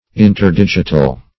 interdigital - definition of interdigital - synonyms, pronunciation, spelling from Free Dictionary
Search Result for " interdigital" : The Collaborative International Dictionary of English v.0.48: Interdigital \In`ter*dig"i*tal\, a. (Anat.) Between the fingers or toes; as, interdigital space.